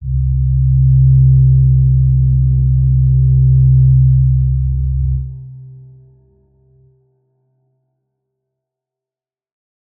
G_Crystal-C3-mf.wav